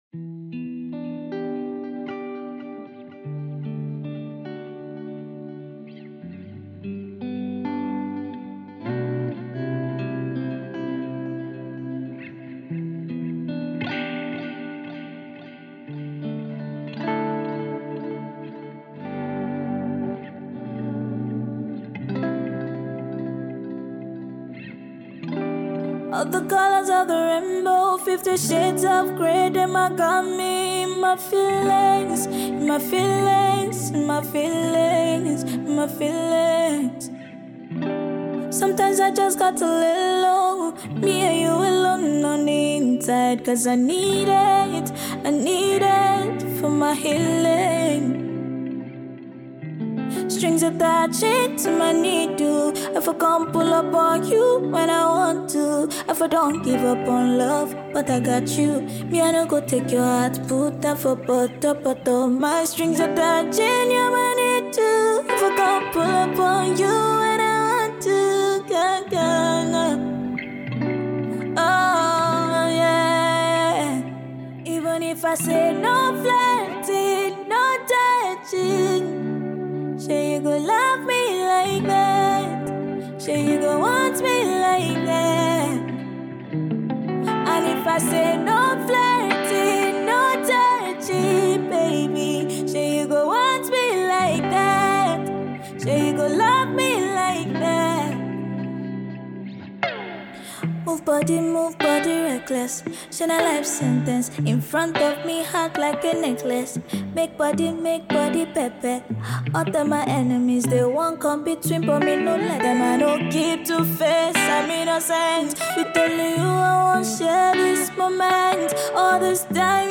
Nigerian Afropop sensation
acoustic version